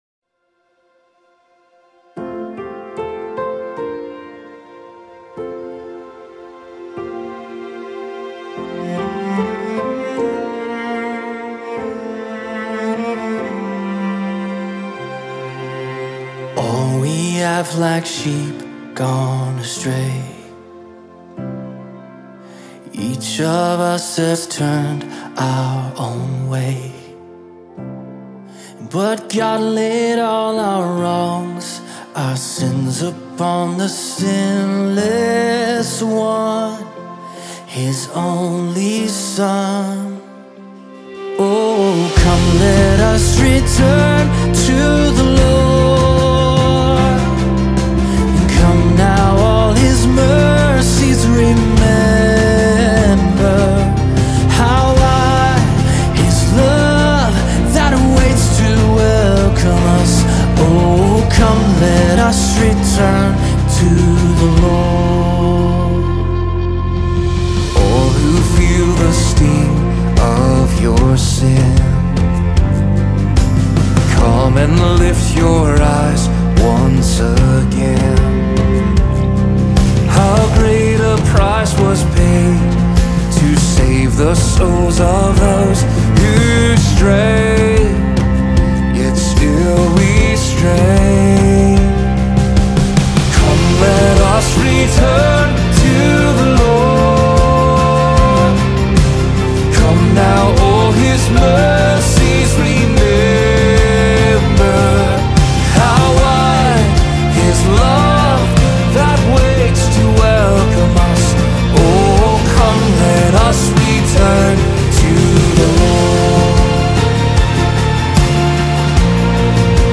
64 просмотра 71 прослушиваний 0 скачиваний BPM: 75